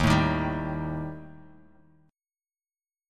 EbMb5 chord